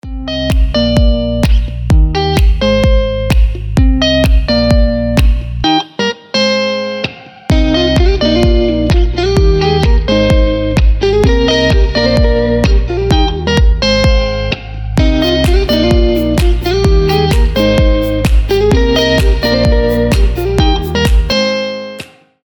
• Качество: 320, Stereo
гитара
без слов
красивая мелодия
tropical house
теплые
ремиксы
Милая мелодия